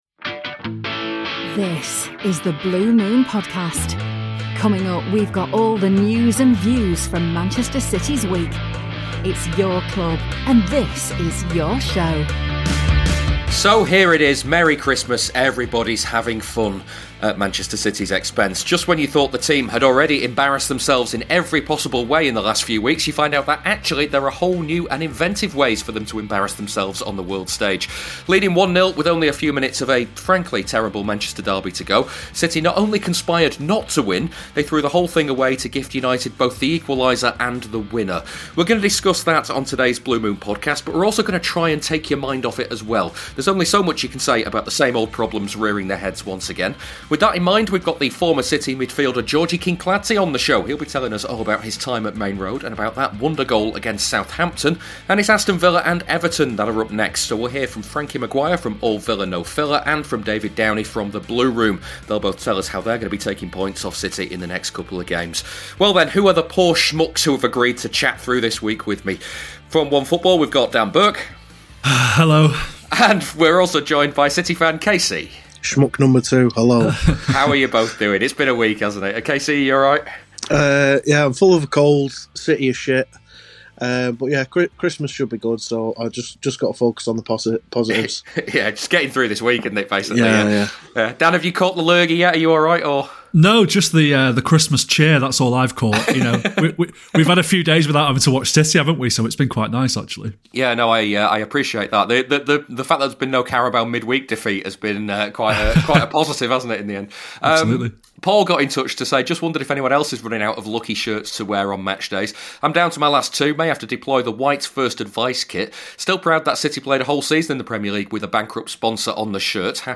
We hear from the former City midfielder Georgi Kinkladze, as he speaks to the show about his three seasons at Maine Road.